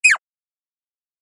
суслик тихо пискнул